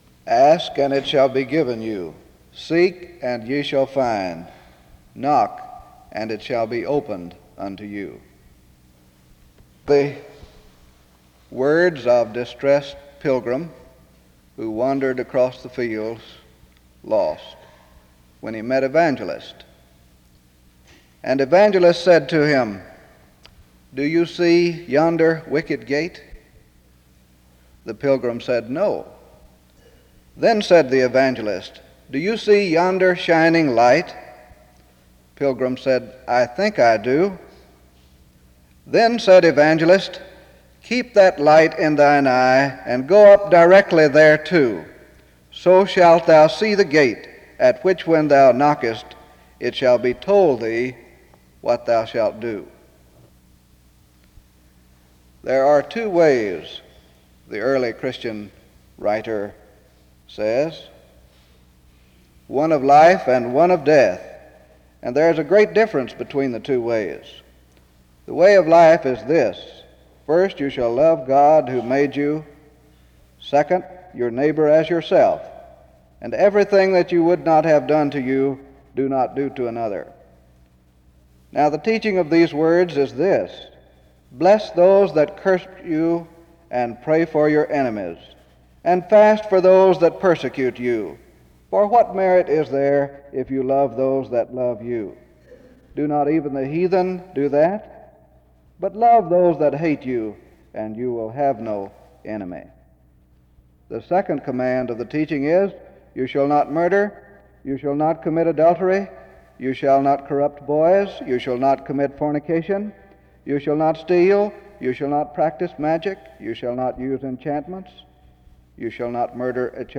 [The original audio cuts off before message is finished.]
SEBTS Chapel and Special Event Recordings SEBTS Chapel and Special Event Recordings